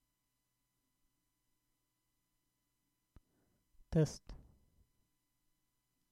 I use cheap XLR Sure SV200 mic.
Additional info: noise present on recording, noise present with mic umplugged, noise present when I use card with windows 7 desktop or ubuntu laptop.
I attached noise exemple but it has additional noises from mp3 compression since forum doesnt allow flac attachments.